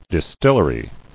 [dis·till·er·y]